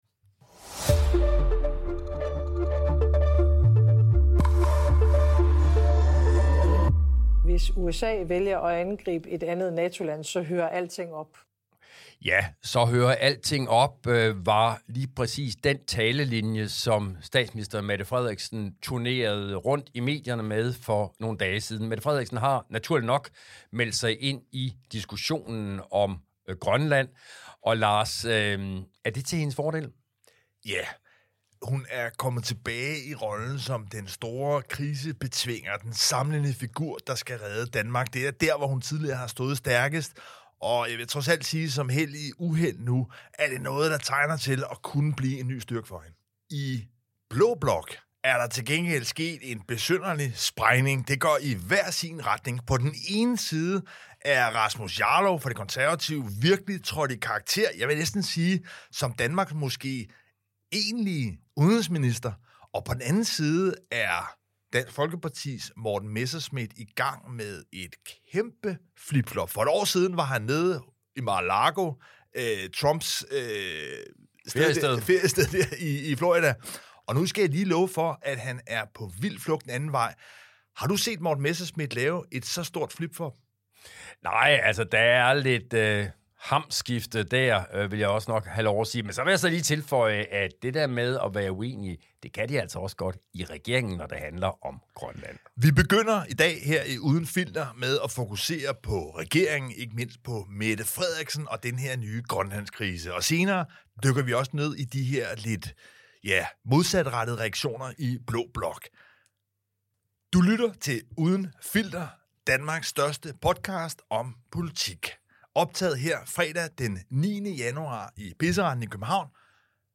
Og hvad vil være Danmarks modtræk, hvis USA snart tilbyder en kæmpe dollar-check til alle grønlændere? Hør de to politiske kommentatorer Lars Trier Mogensen og Henrik Qvortrup analysere ugens vigtigste begivenheder i dansk politik.
Værter: Henrik Qvortrup og Lars Trier Mogensen